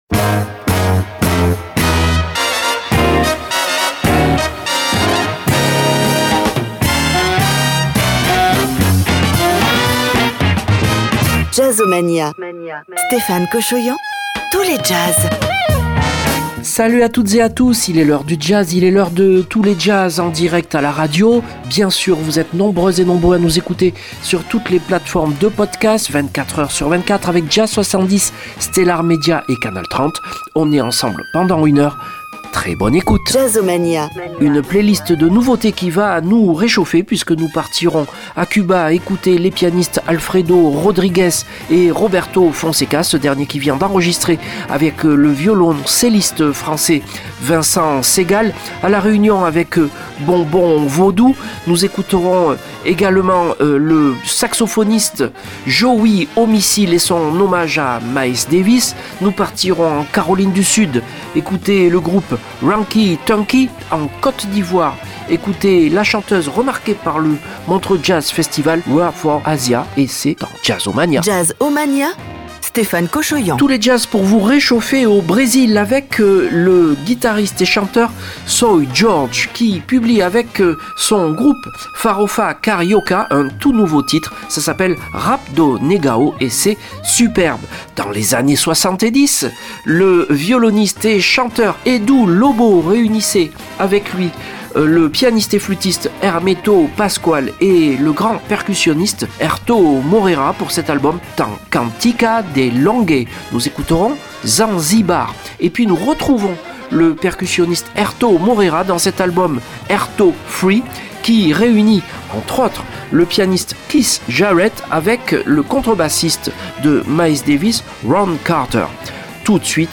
en live le dimanche 18h00